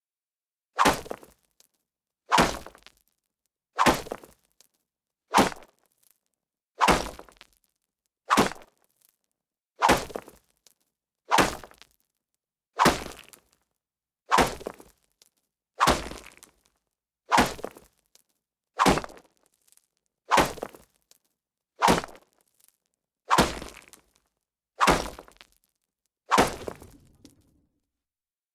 Wooden Spear On Stone Wall